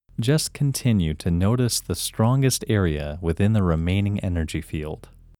IN – First Way – English Male 16
IN-1-English-Male-16.mp3